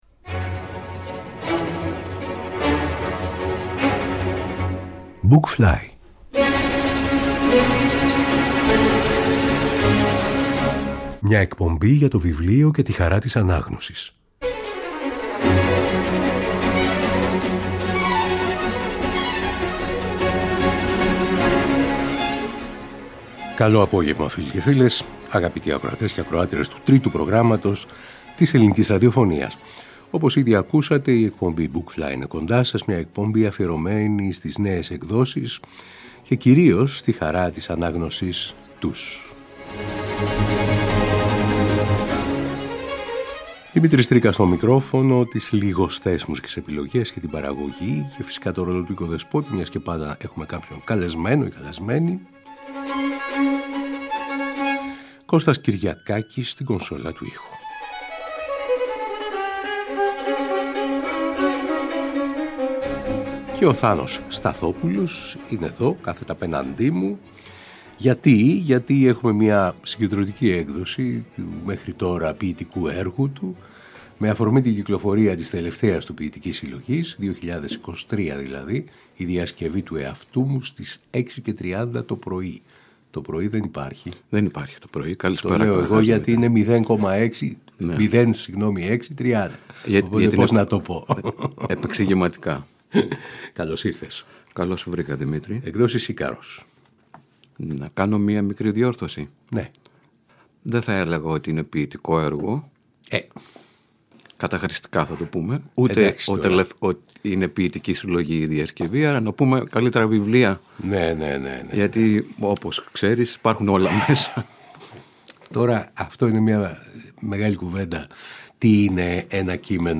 Κύκλος εκπομπών για το βιβλίο και τη χαρά της ανάγνωσης